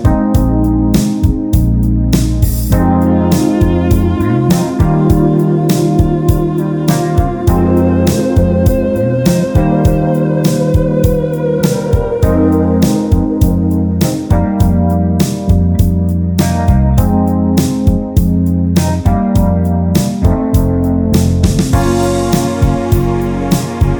no Backing Vocals Soft Rock 4:32 Buy £1.50